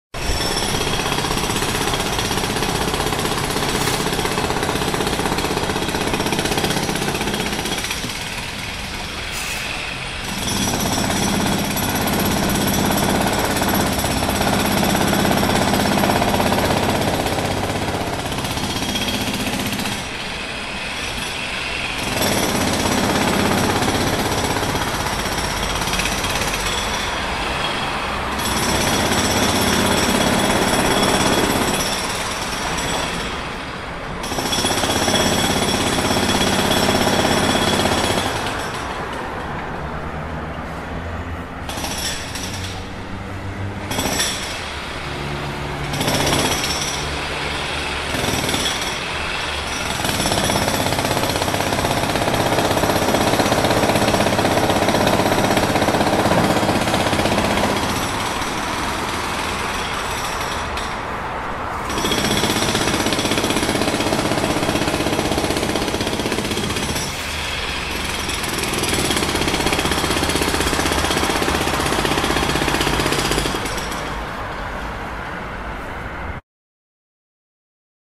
دانلود آهنگ هیلتی یا چکش مکانیکی 1 از افکت صوتی اشیاء
جلوه های صوتی